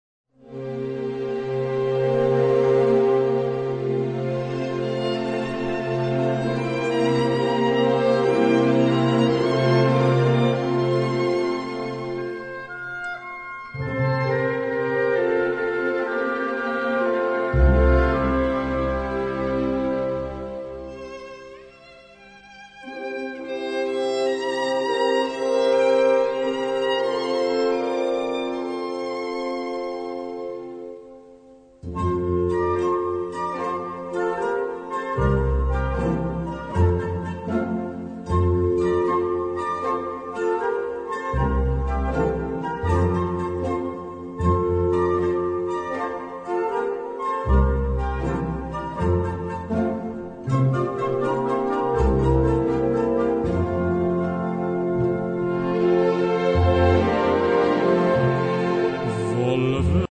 instrumentation: 3/2/2/2 - 4/0/Tb. - Perc. - Harp - Strings
key: F-major